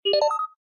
ships / computer / announce2.ogg
announce2.ogg